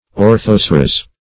Search Result for " orthoceras" : The Collaborative International Dictionary of English v.0.48: Orthoceras \Or*thoc"e*ras\, n. [NL., fr. Gr.